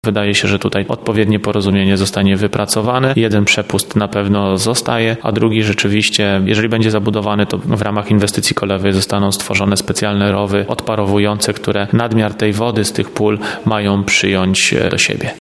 Mówi prezydent Łukasz Nowak.